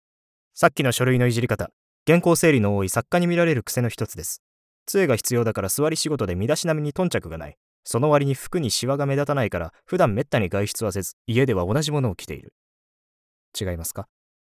ボイスサンプル
セリフA